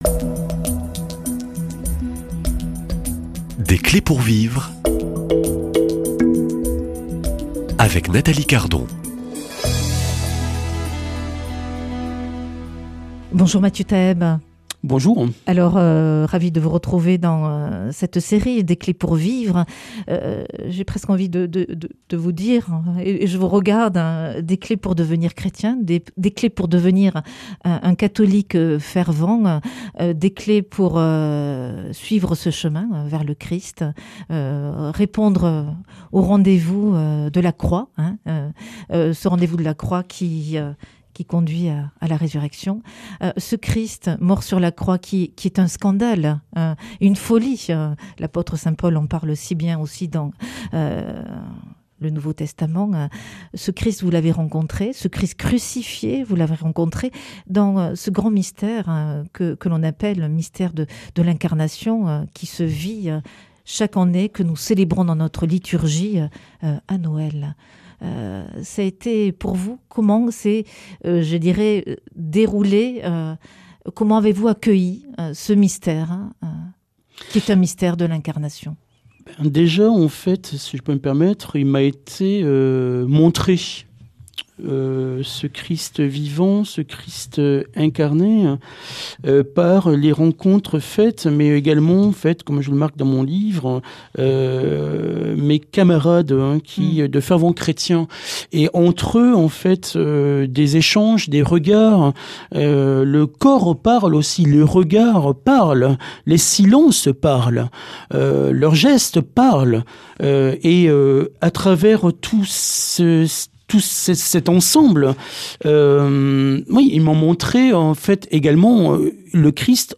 Accueil \ Emissions \ Foi \ Témoignages \ Des clés pour vivre \ Jésus est une personne avec qui je vis tous les jours !